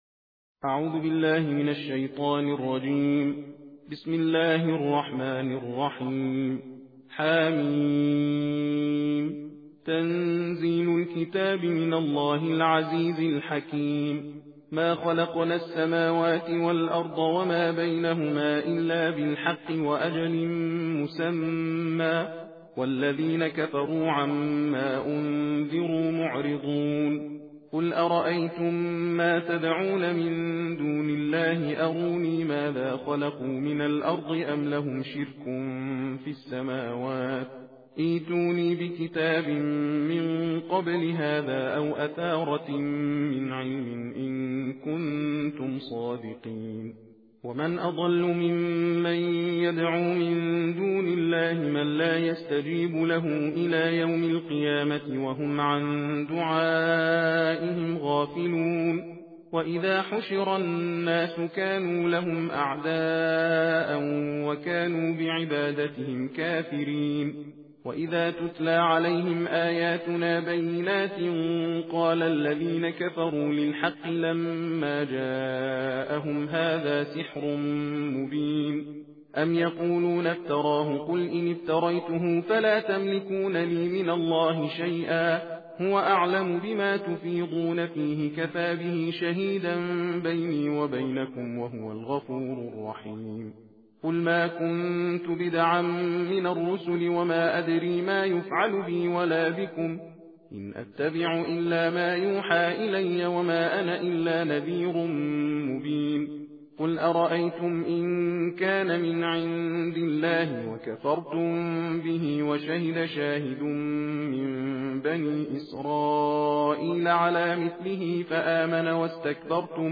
تندخوانی جزء بیست و ششم قرآن کریم - مشرق نیوز
صوت/ تندخوانی جزء بیست و ششم قرآن کریم